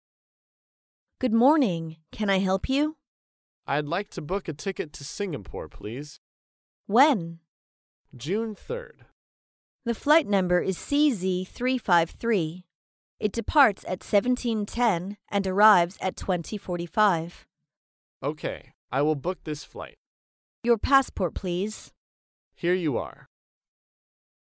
在线英语听力室高频英语口语对话 第414期:预订飞机票(1)的听力文件下载,《高频英语口语对话》栏目包含了日常生活中经常使用的英语情景对话，是学习英语口语，能够帮助英语爱好者在听英语对话的过程中，积累英语口语习语知识，提高英语听说水平，并通过栏目中的中英文字幕和音频MP3文件，提高英语语感。